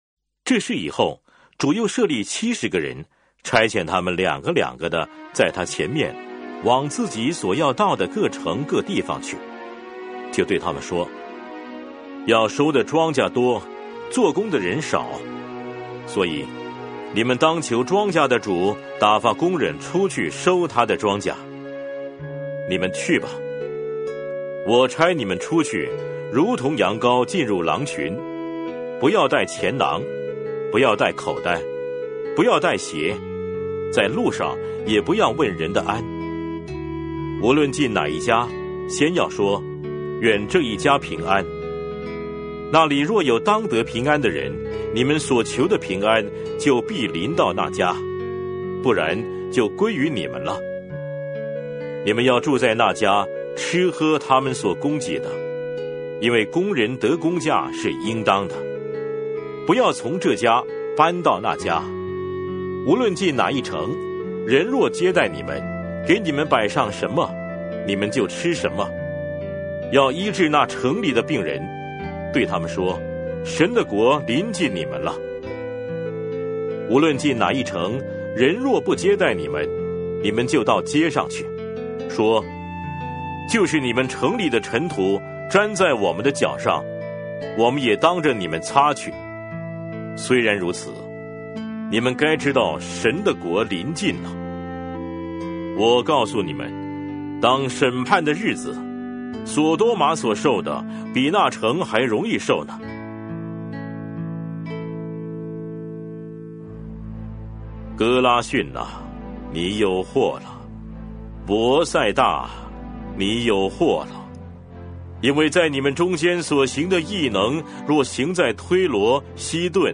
每日读经 | 路加福音10章
以上音频由汉语圣经协会录制